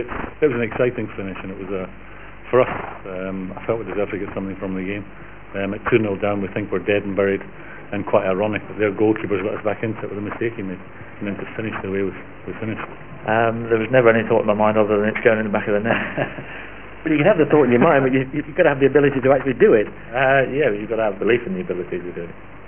Le Tiss speaks after scoring - The man himself, (the file is slightly messed up, Le Tiss sounds like he's on speed! 121kb